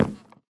update audio to prevent artifacts
wood1.ogg